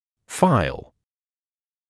[faɪl][файл]задокументированная история болезни пациента; файл (в т.ч. эндодонтический)